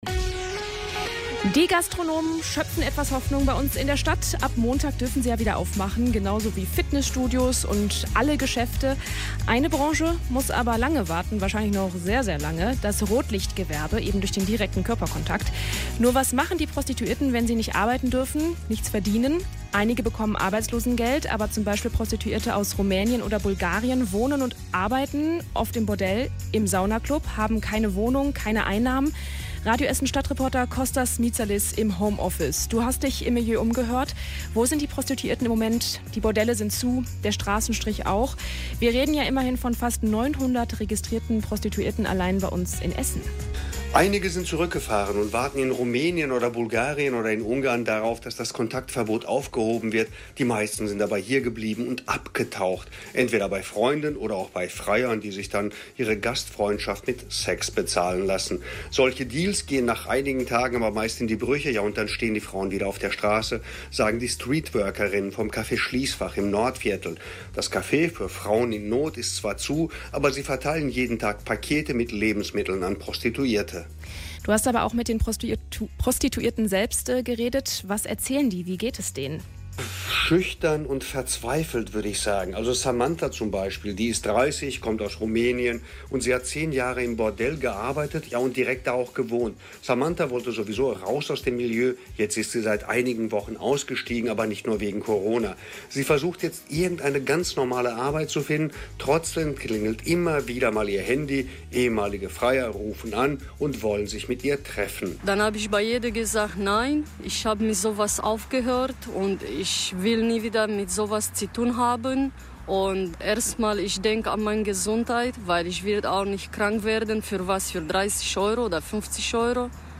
mitschnitt-kg-mit-prostituierten.mp3